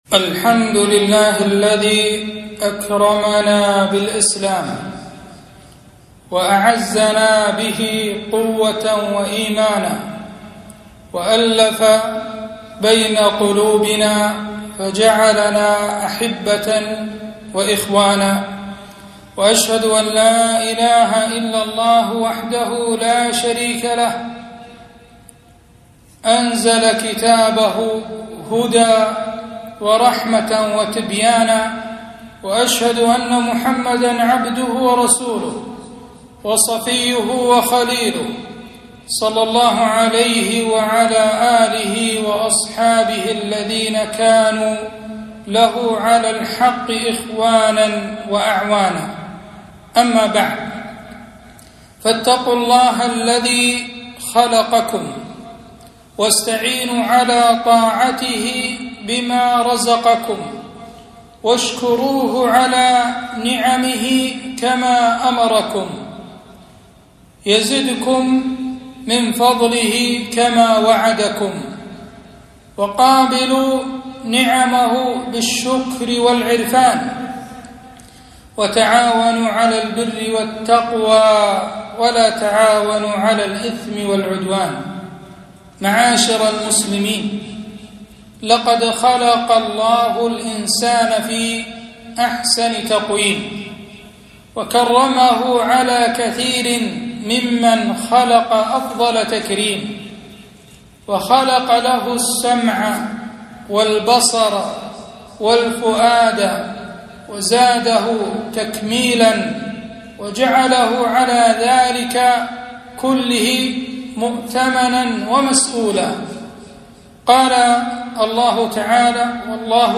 خطبة - مسؤولية الكلمة